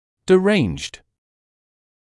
[dɪ’reɪnʤd][ди’рэйнджд]имеющий нарушения; душевнобольной